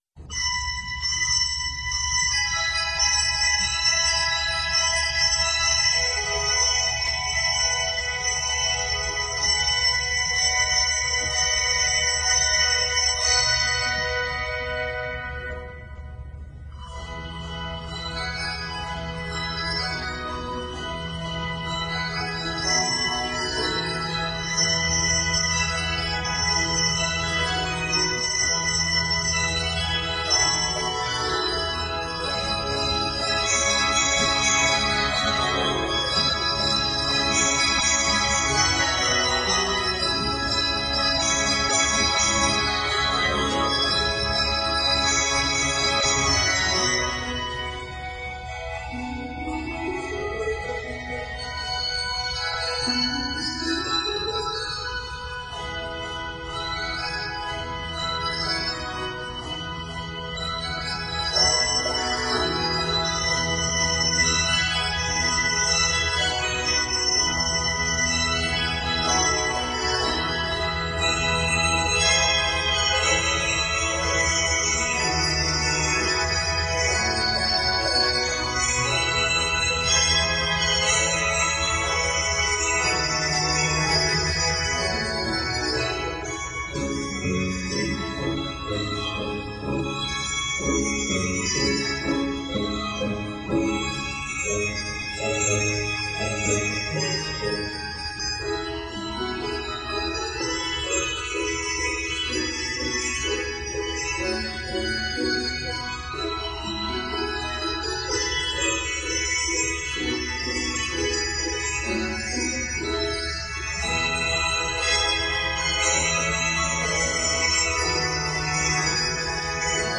Review: A show piece for the advanced bell choir.